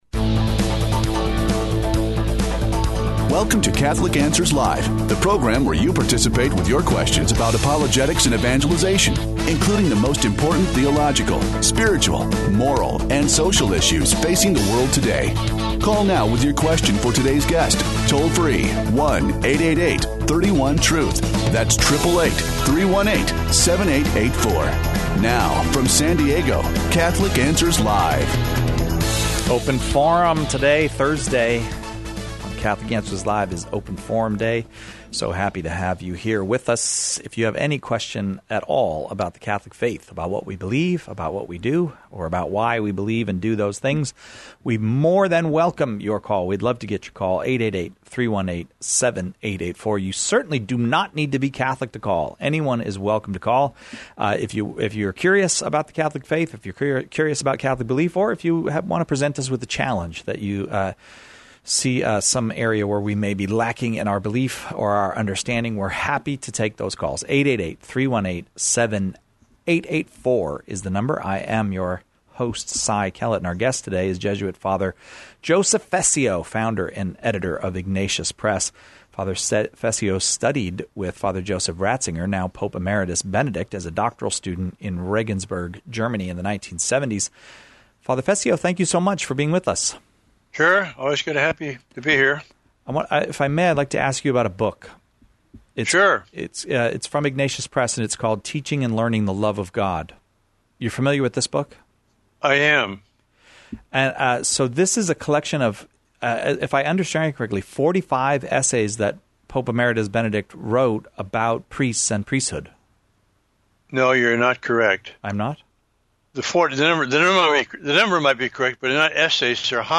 The callers choose the topics during Open Forum with questions on every aspect of Catholic life and faith, the moral life, and even philosophical topics.